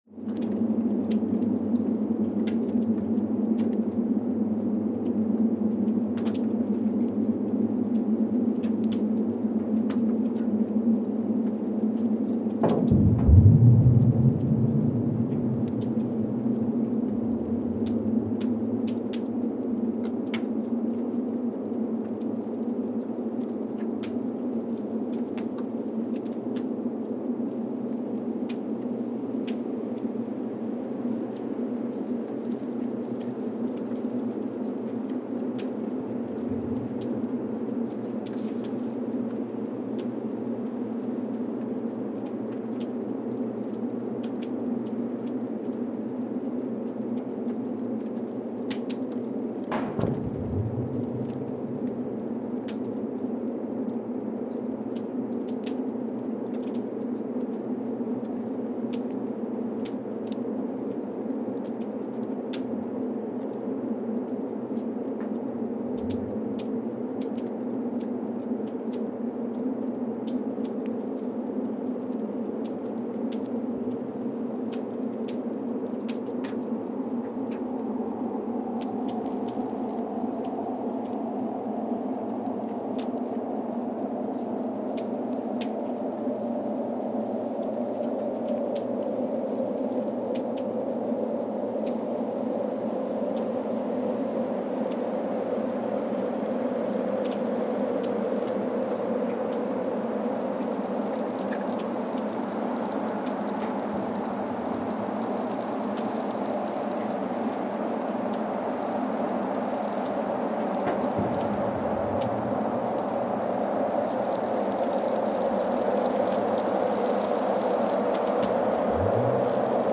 Casey, Antarctica (seismic) archived on September 13, 2020
Station : CASY (network: GSN) at Casey, Antarctica
Sensor : Streckheisen STS-1VBB
Speedup : ×1,800 (transposed up about 11 octaves)
Loop duration (audio) : 05:36 (stereo)
Gain correction : 25dB
SoX post-processing : highpass -2 90 highpass -2 90